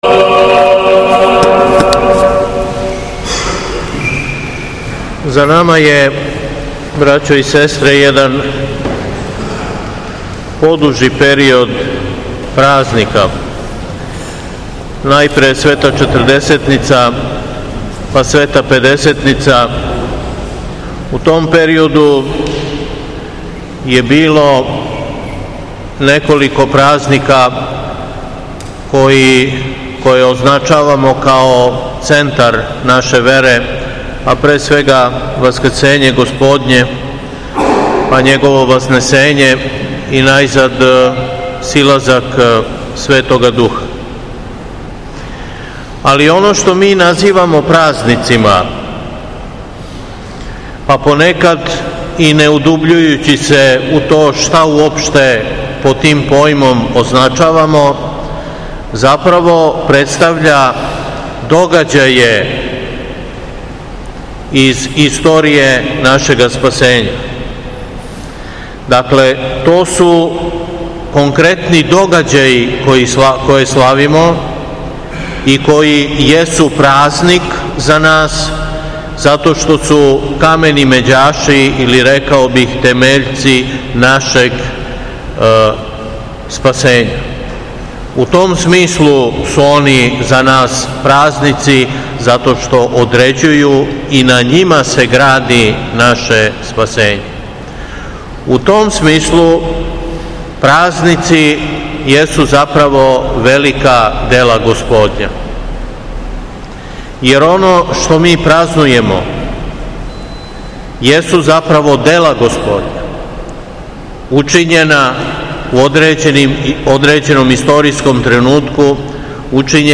СВЕТА ЛИТУРГИЈА И ПОМЕН БЛАЖЕНОПОЧИВШЕМ ЕПИСКОПУ ДР САВИ (ВУКОВИЋУ) У САБОРНОМ ХРАМУ У КРАГУЈЕВЦУ - Епархија Шумадијска
Беседа